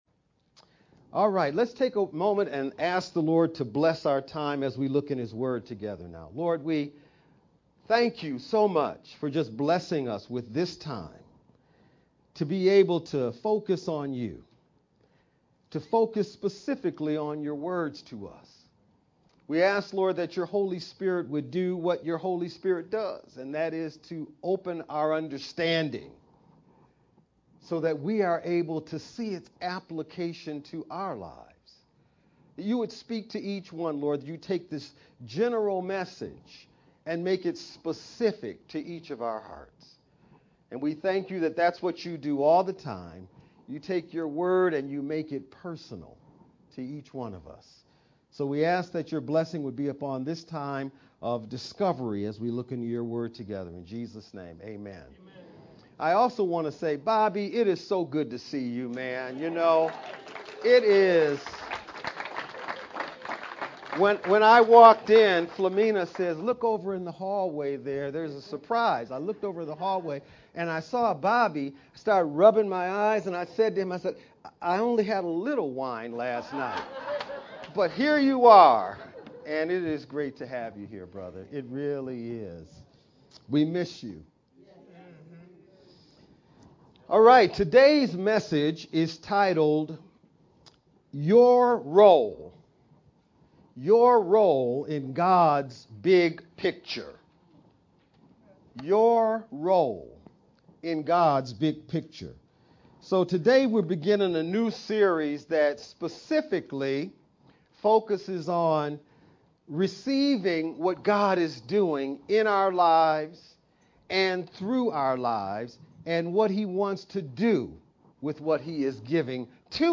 VBCC-Sermon-edited-2-5-sermon-only-CD.mp3